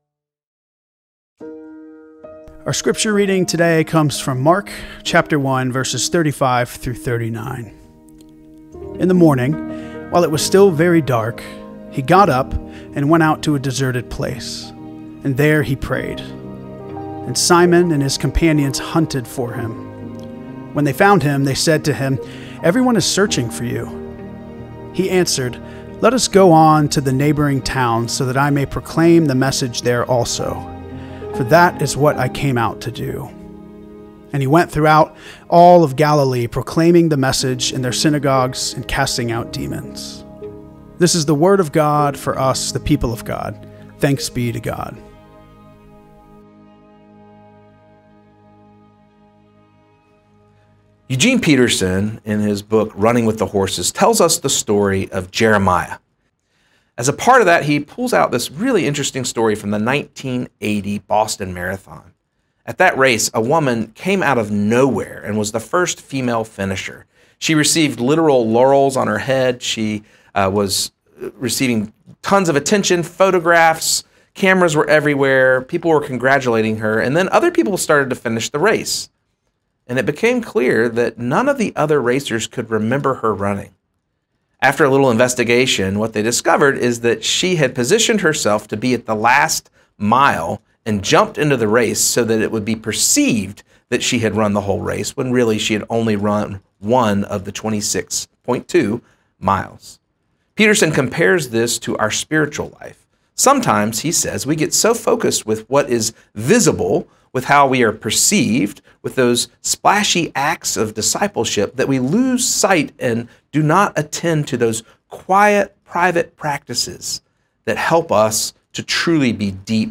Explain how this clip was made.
First Cary UMC’s Sermon (community-wide worship)